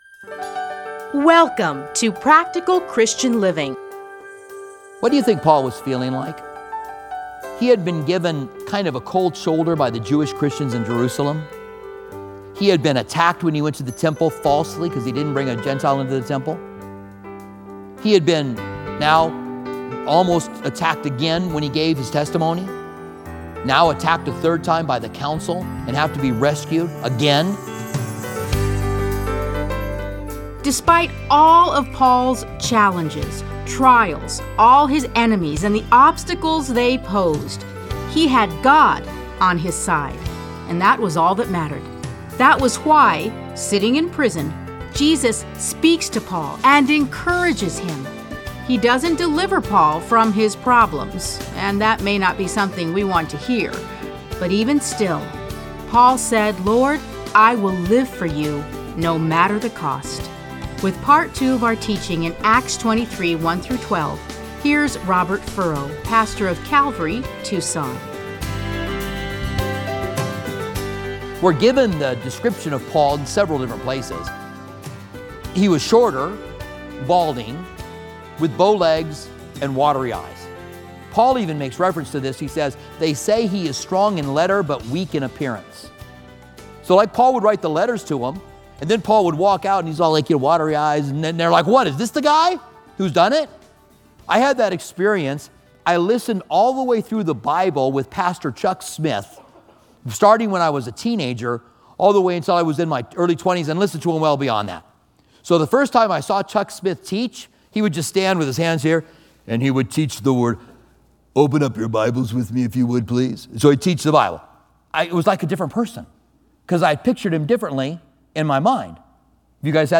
Listen to a teaching from Acts 23:1-12.